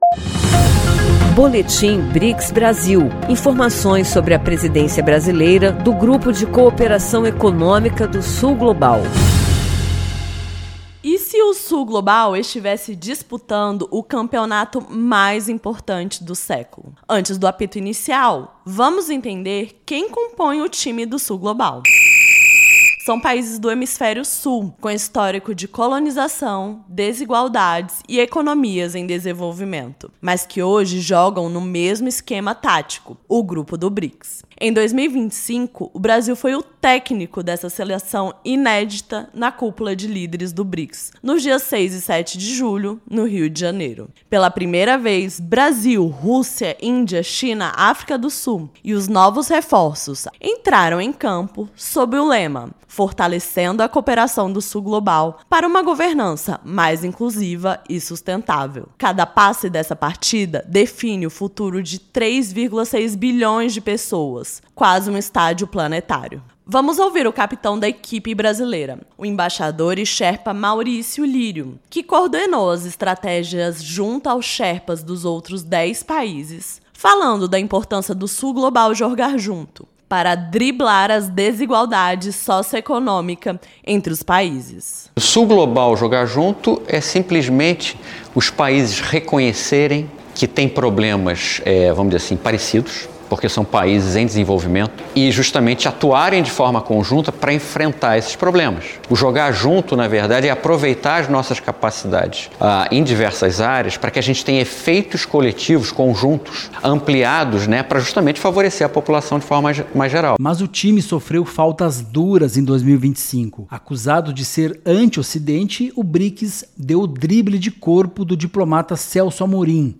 BRICS debate turismo regional, sustentável e cooperação em reunião liderada pelo Brasil. A reunião teve foco em emprego, infraestrutura e desenvolvimento comunitário, com destaque para crescimento pós-pandemia. Ouça a reportagem e saiba mais.